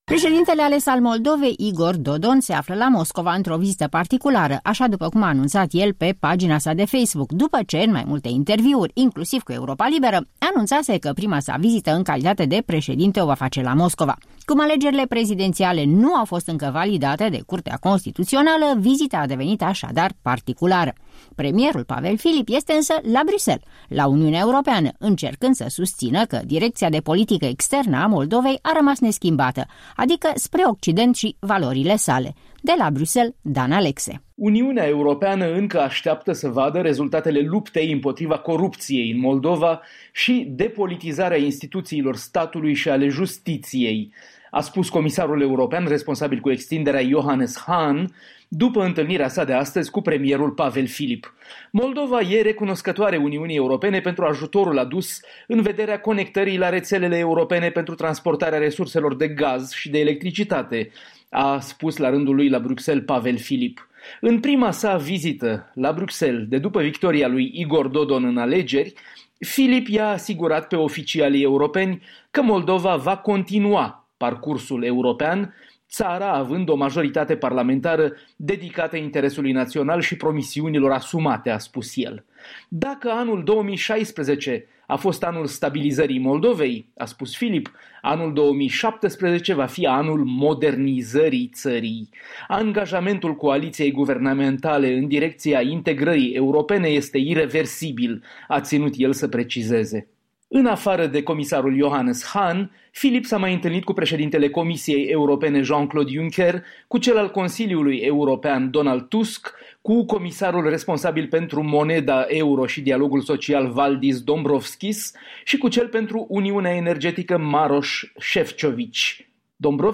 În direct de la Bruxelles